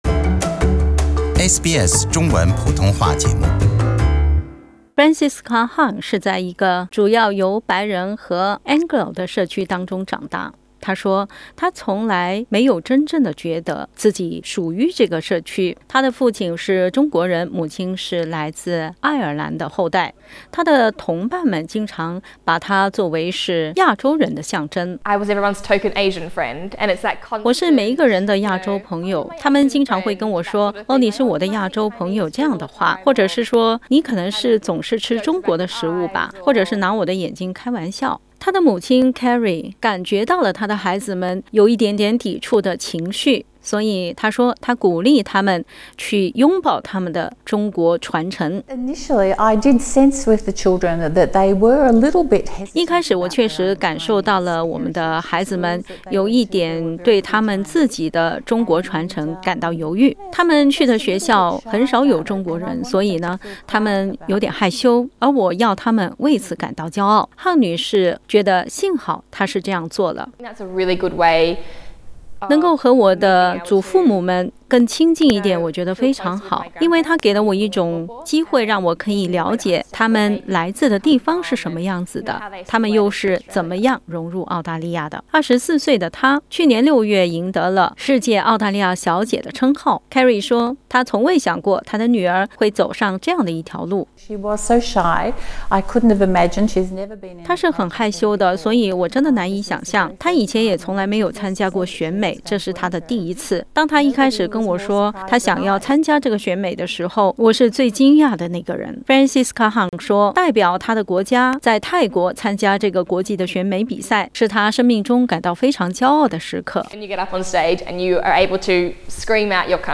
接受SBS采访